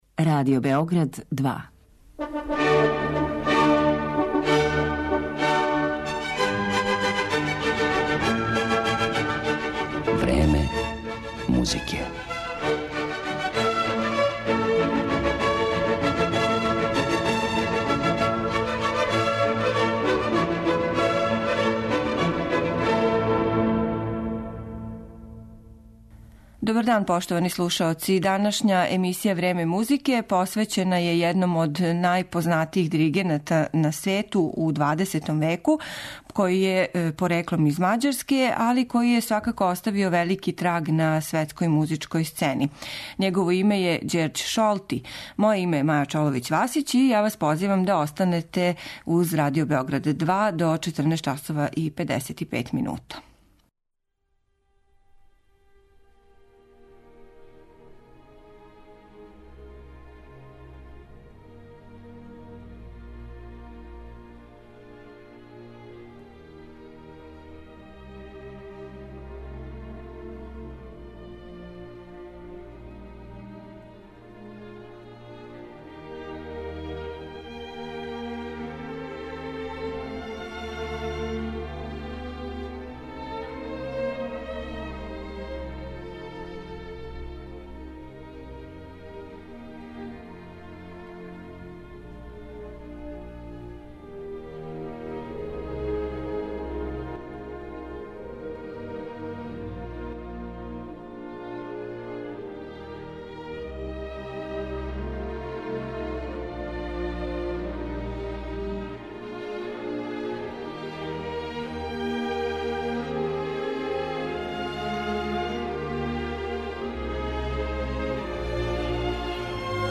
Његов портрет осликаћемо музиком Чајковског, Малера, Елгара, Вагнера и Бартока.